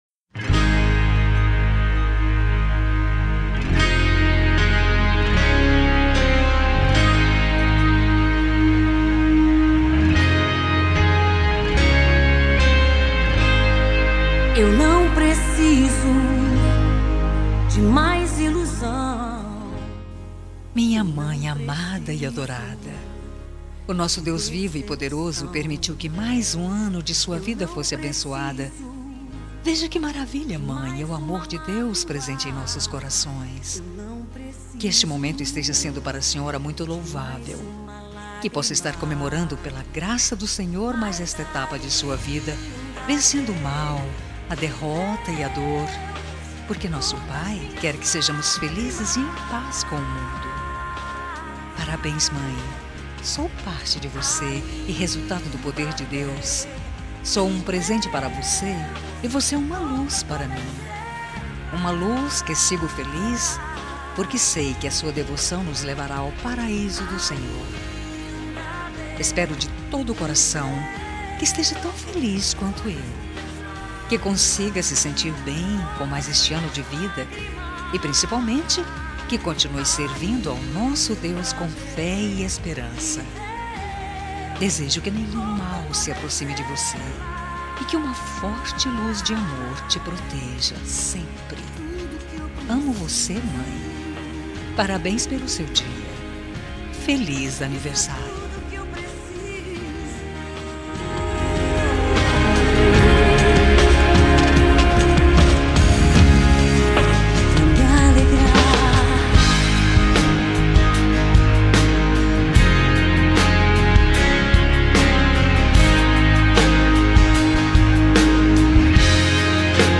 Telemensagem Evangélica Anversário Mãe | Com Reação e Recado Grátis